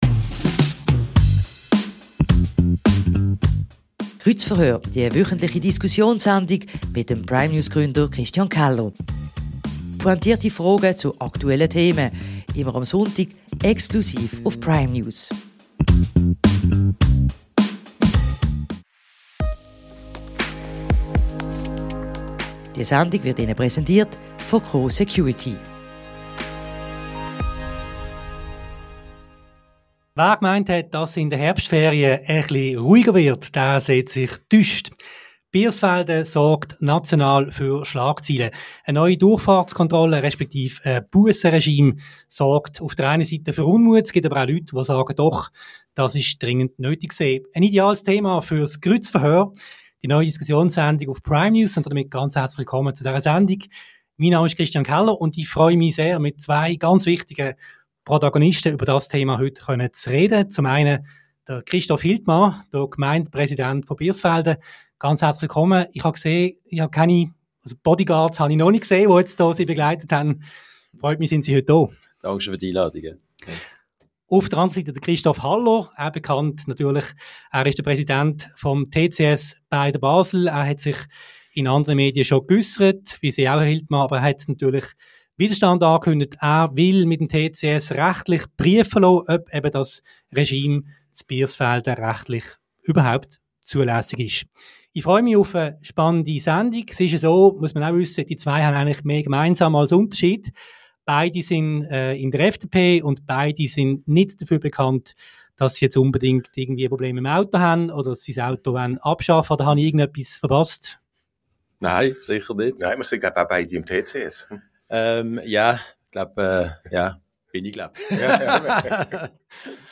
Streitgespräch